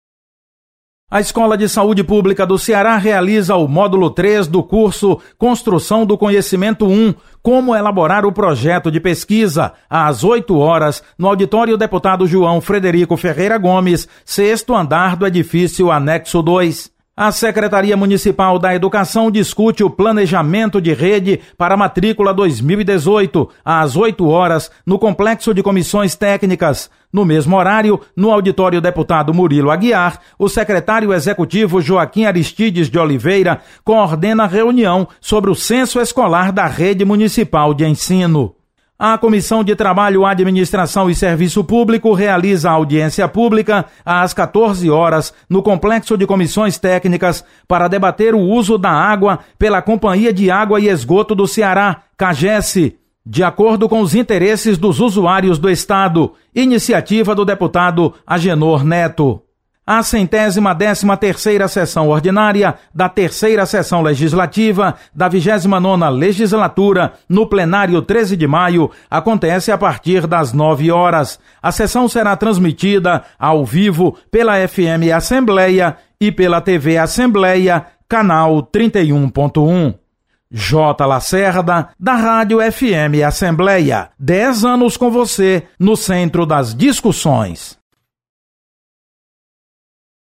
Acompanhe as atividades desta sexta-feira na Assembleia Legislativa. Repórter